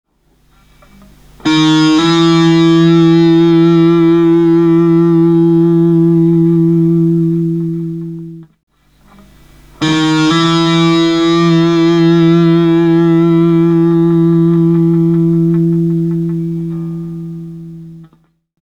Zazní skluz, neboli vázaná nota - dva spojené tóny za sebou.
Níže v tabulatuře je vyznačen na struně "d" skluz z prvního do druhého pole.
Skluz (Slide) na kytaru